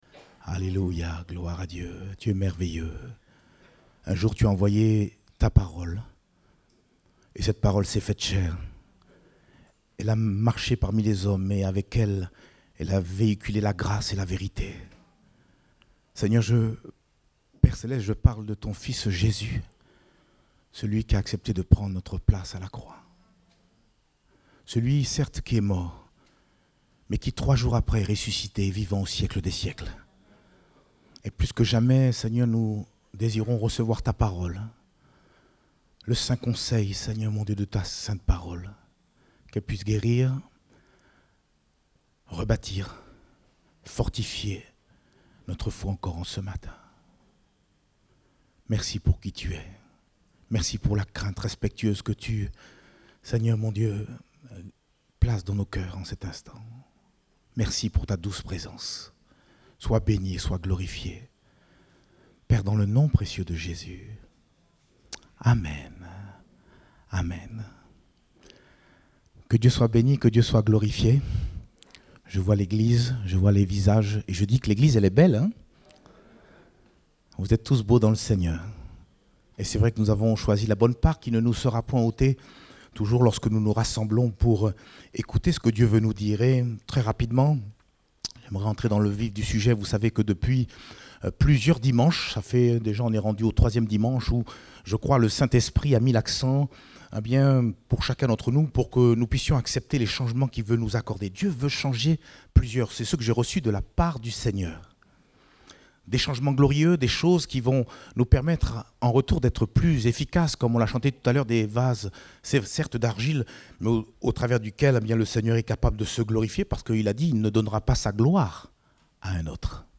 Date : 26 septembre 2021 (Culte Dominical)